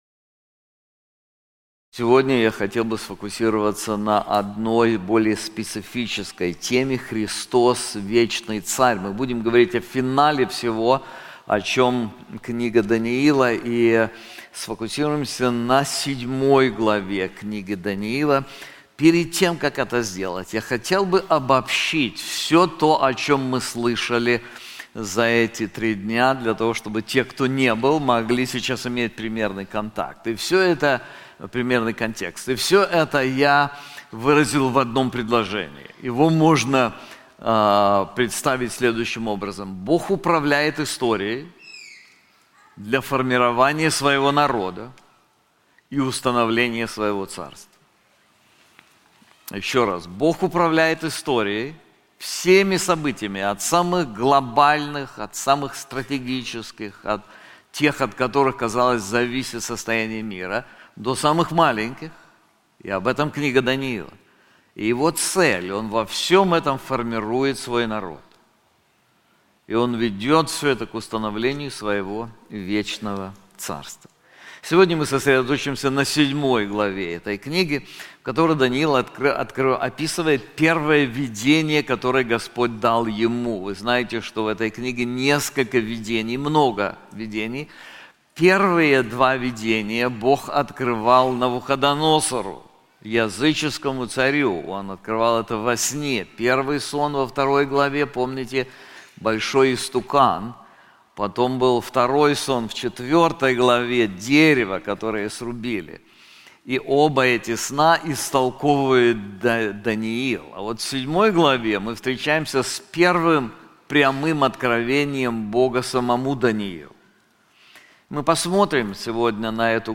На конференции "Владыка истории" мы рассмотрим книгу пророка Даниила, каждая глава которой ясно показывает, что история находится в руках Бога. Лишь осознав, что нашим миром управляет не хаос, а всевластный Бог, мы сможем уверенно смотреть в будущее, оставаясь верными и усердными в служении.